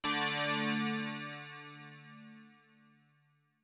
music game, ding 2
ding game game-music music music-game sound effect free sound royalty free Music